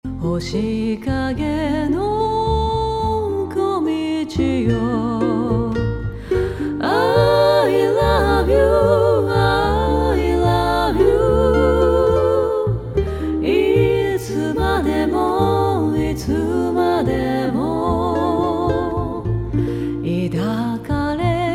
９割Ｍｉｘ済み